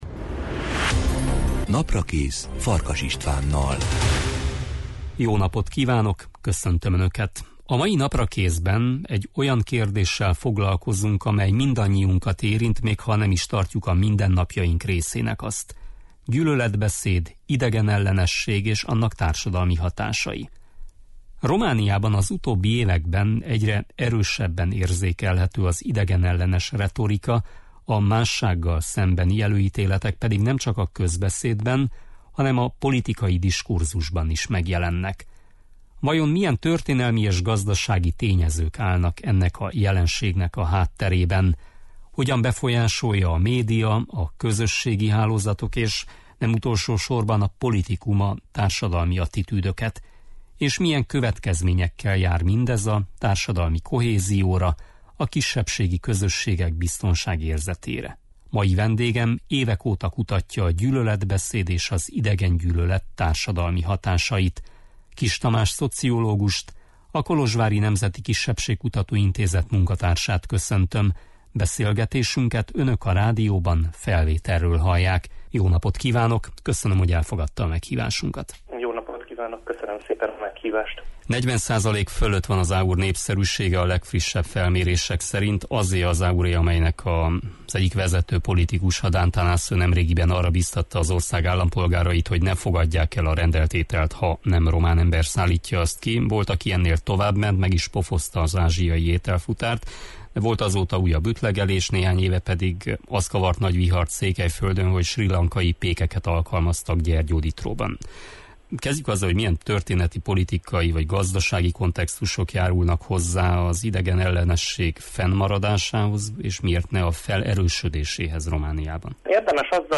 Mai vendégem évek óta kutatja a gyűlöletbeszéd és az idegengyűlölet társadalmi hatásait.